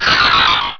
Cri de Stalgamin dans Pokémon Rubis et Saphir.